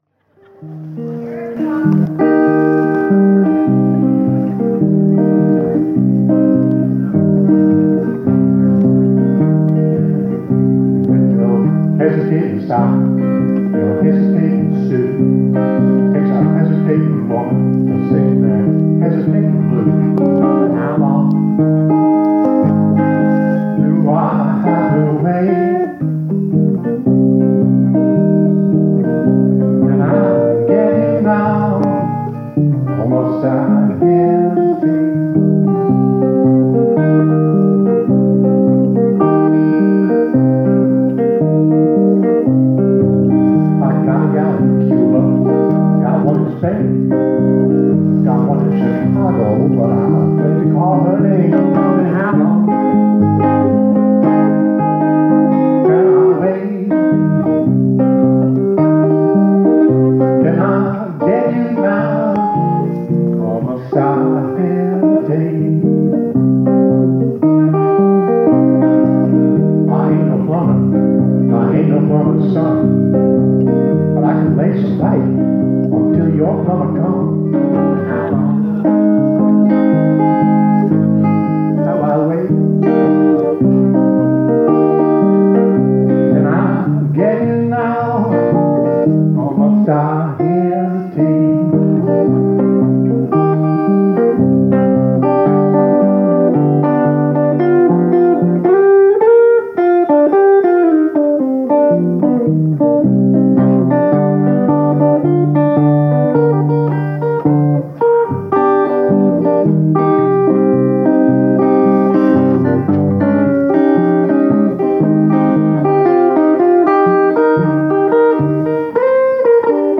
Standard Tuning [E,A,D,G,B,e]
Hesitating Blues - traditional 1916 (capo 3) - W vid ref short vid ref